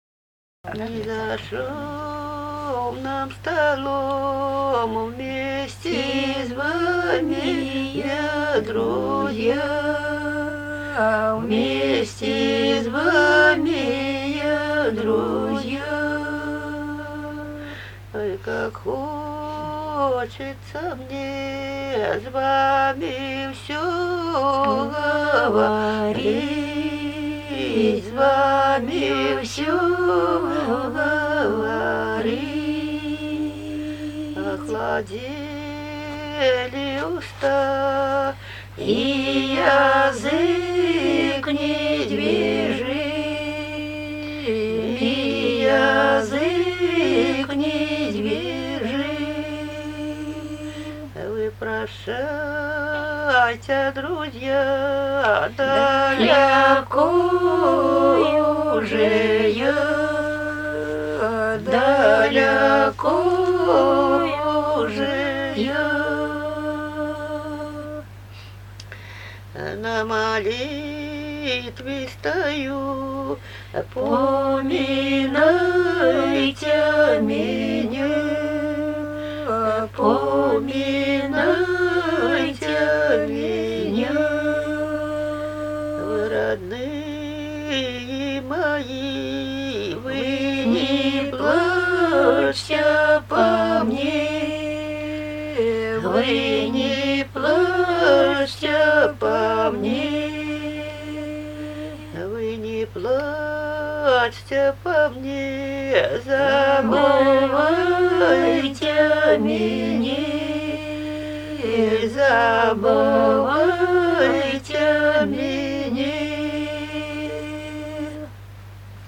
Сходство с церковными песнопениями проявляется в так называемой «обиходной» гармонизации их мелодий. Напевы стихов представляют собой своеобразную мелодическую интерпретацию 4-хголосной ткани церковных песнопений.
Напевы духовных стихов опираются на весьма традиционные ритмические формы.
01 Духовный стих «Не за шумным столом» в исполнении жительниц д. Марьино Глинковского р-на Смоленской обл.